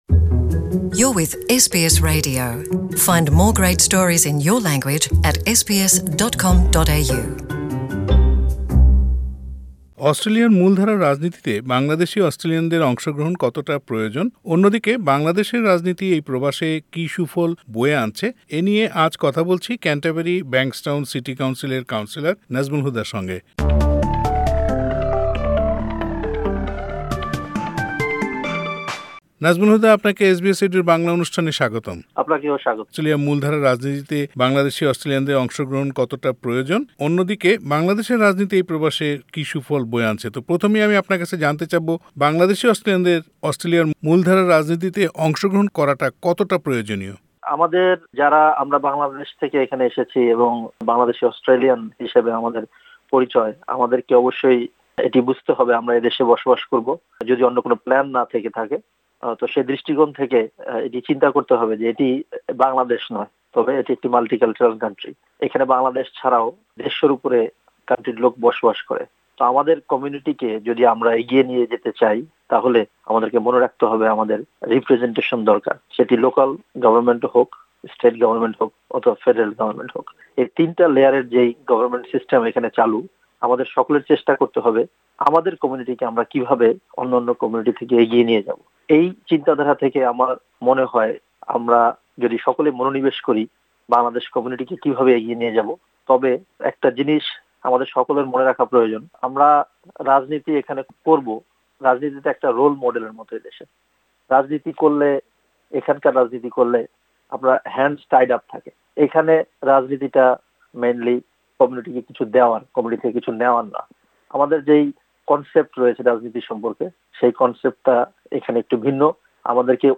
এসব নিয়ে এসবিএস বাংলার সঙ্গে কথা বলেছেন কেন্টারবেরি-ব্যাংকসটাউন সিটি কাউন্সিলের কাউন্সিলর মোহাম্মদ নাজমুল হুদা।